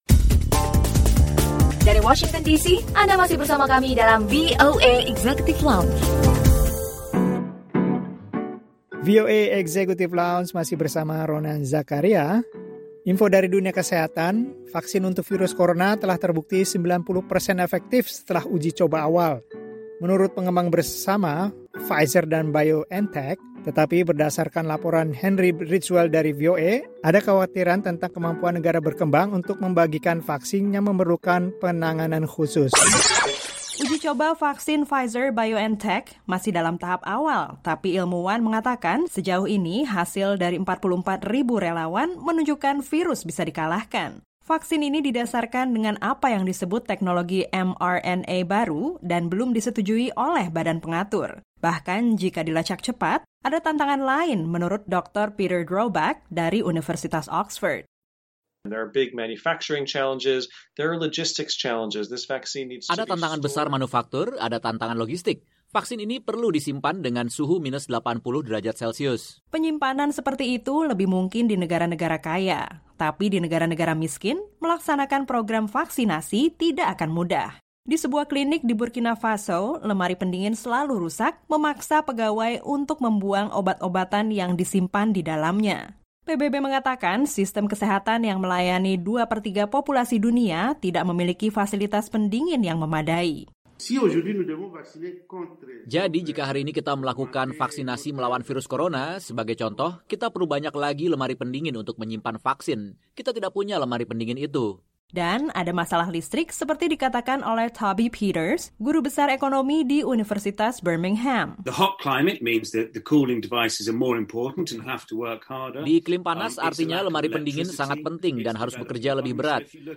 VOA Executive Lounge: Bincang-bincang